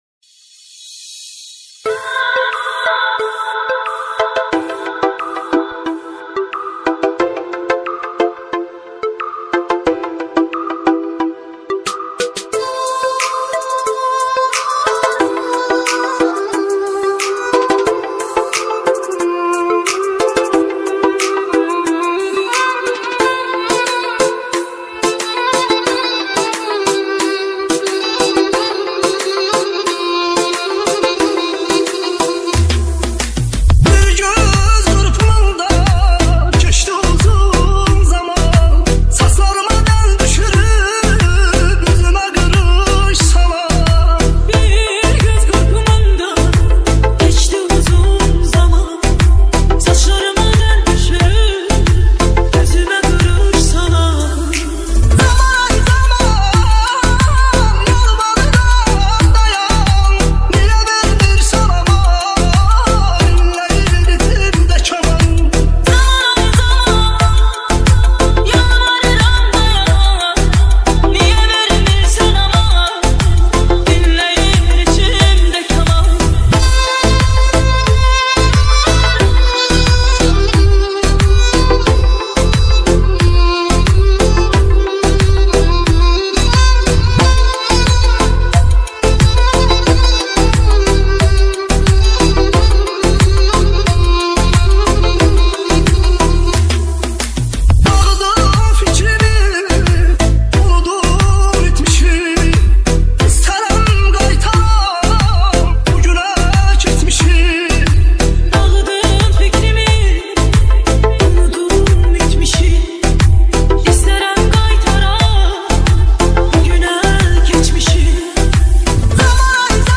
Bass Bossted
DJ Music